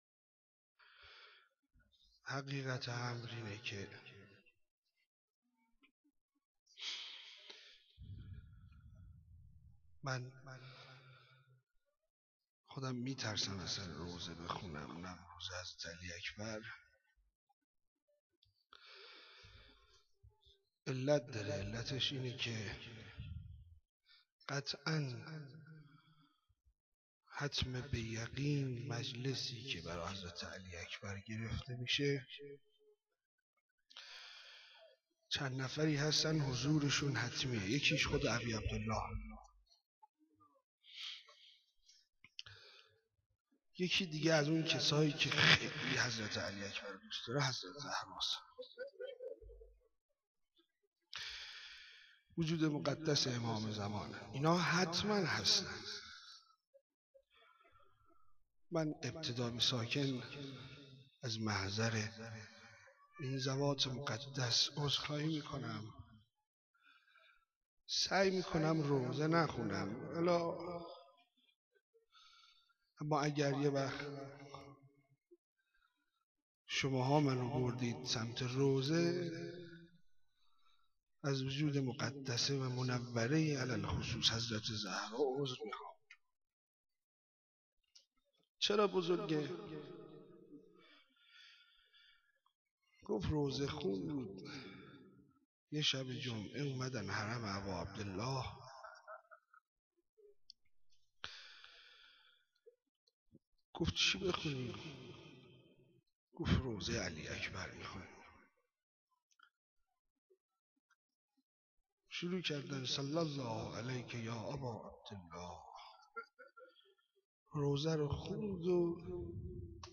روضه-خوانی8.wma